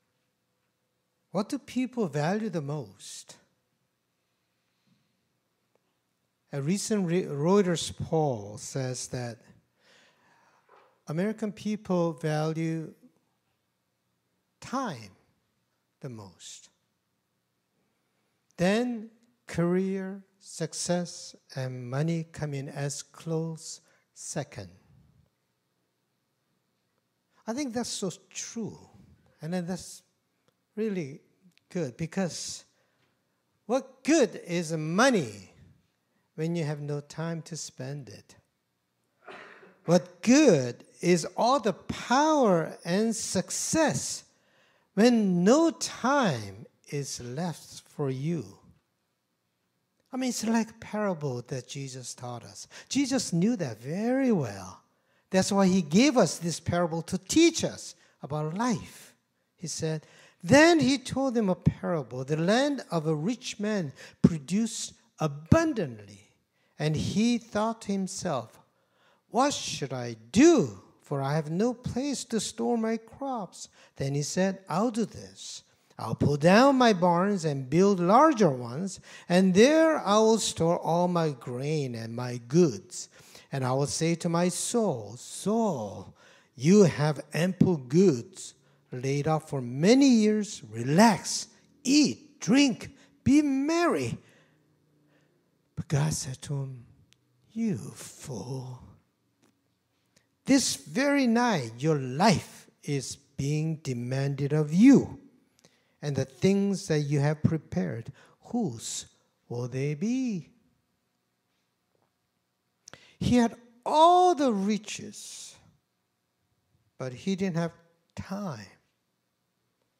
Scripture Passage Philippians 3:4b-14 Worship Video Worship Audio Sermon Script What do people value the most?